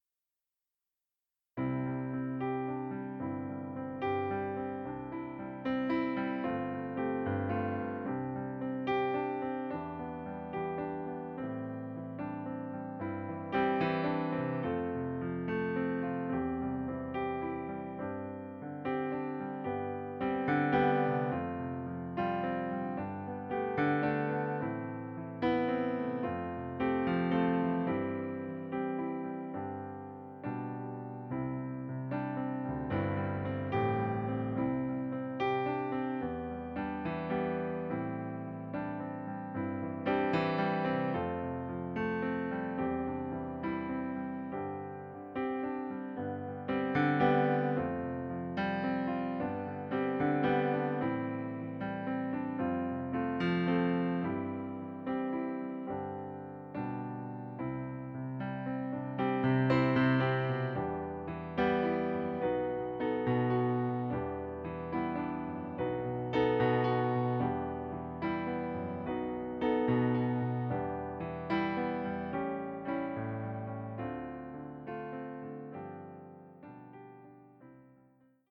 Momentan verfügbar in C-Dur.
C-dur